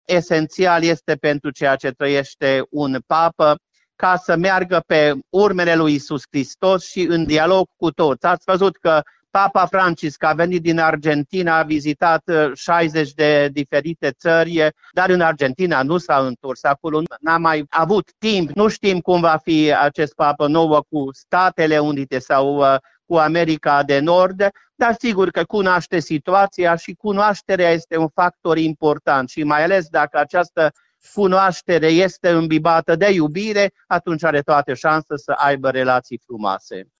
Iosif-Csaba-Pal-Papa-Leon-2.mp3